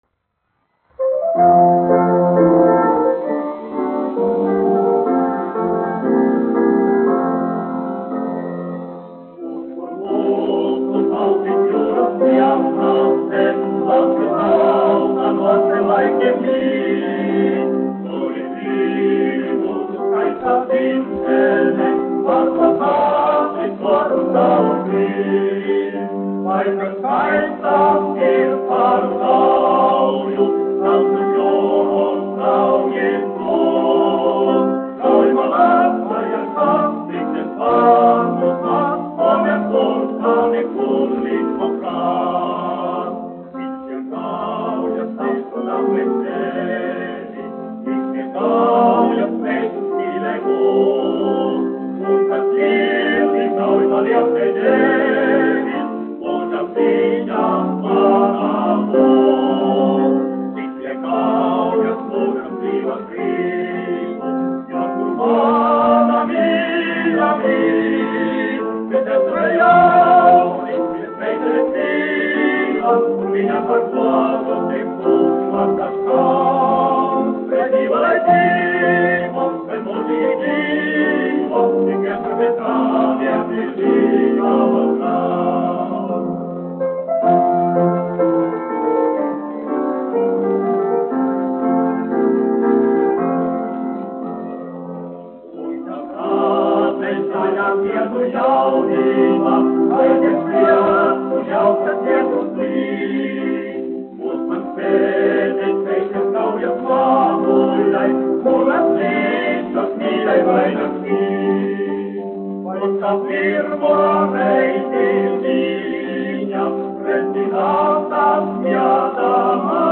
1 skpl. : analogs, 78 apgr/min, mono ; 25 cm
Populārā mūzika
Vokālie seksteti
Skaņuplate